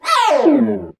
贡献 ） 分类:游戏音效 您不可以覆盖此文件。
se_notice.mp3